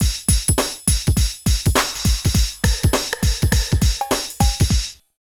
113 DRM LP-L.wav